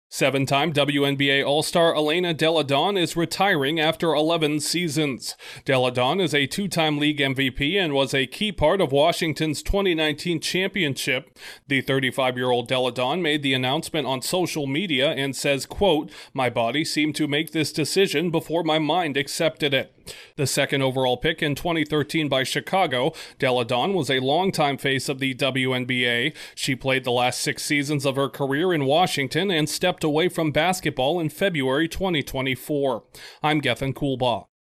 One of the staples of women’s basketball in the 2010s is calling it a career. Correspondent